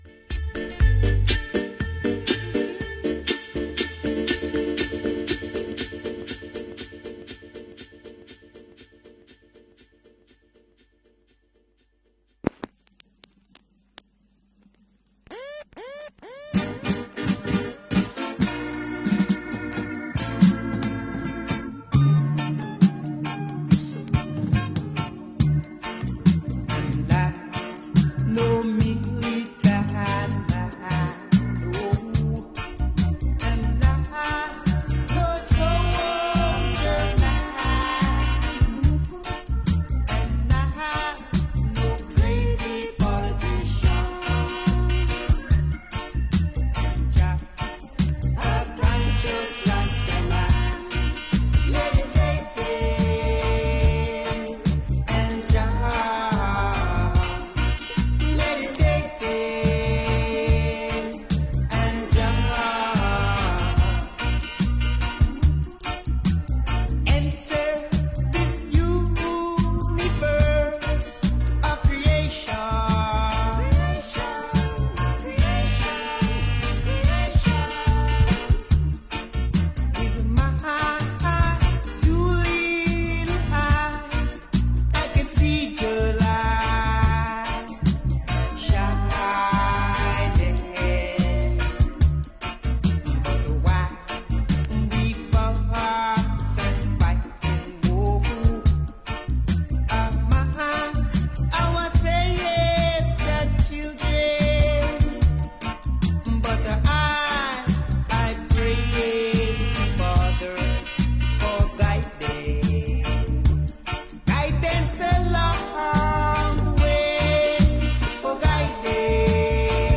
NOTES: 20 mins muted throughout the first 50 mins.